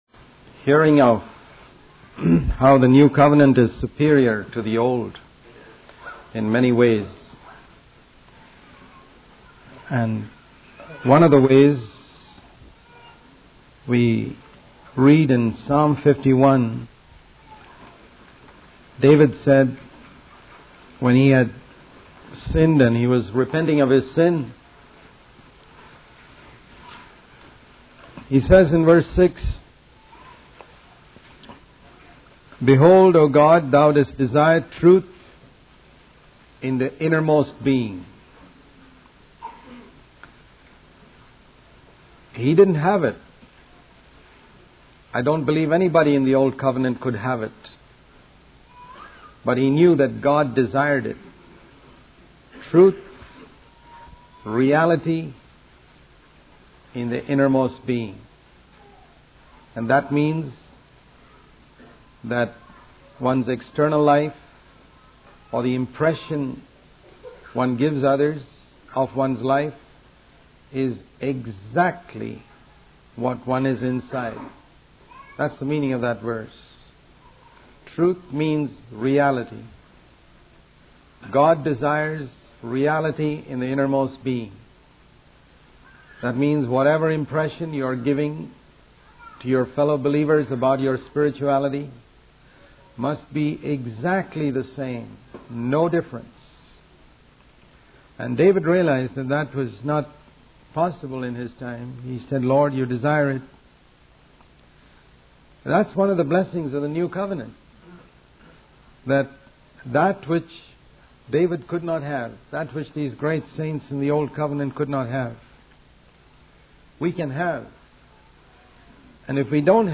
In this sermon, the speaker emphasizes the importance of seeking truth and being honest in our lives. He challenges the idea of approaching the Bible with a logical and mathematical mindset, instead highlighting the need for a heart-to-heart connection with God's word. The speaker warns about the deception of lusts and false gospels, urging listeners to love the truth and develop discernment.